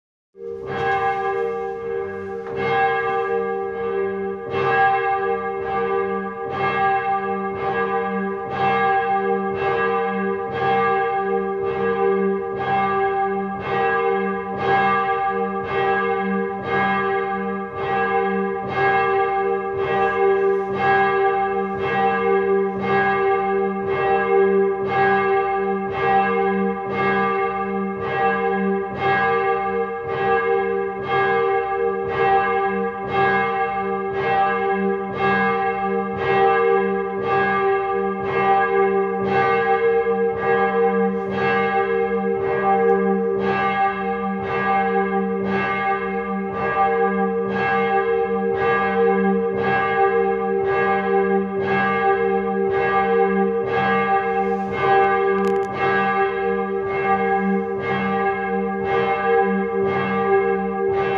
Dann hören wir auf die Glocken, beten das Vaterunser und bitten Gott um seinen Segen und um das Ende dieser Krise.
Glocke.m4a